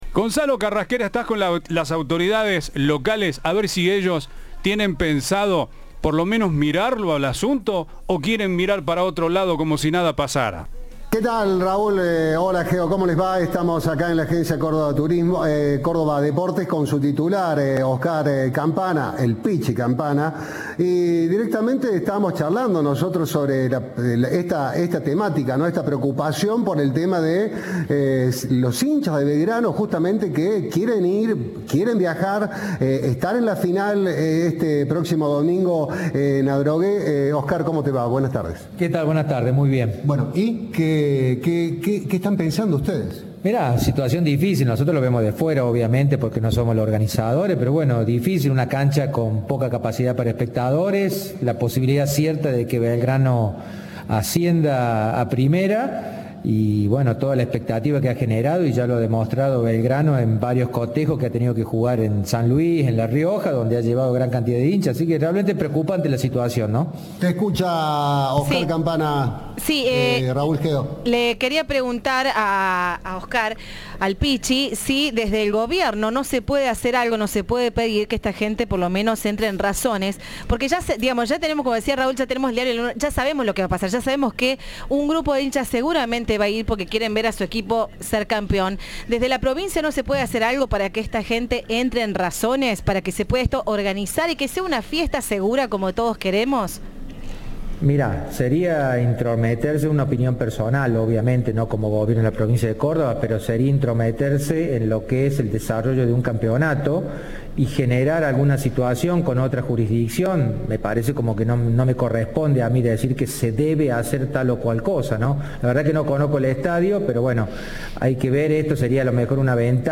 Entrevista de "Viva la Radio"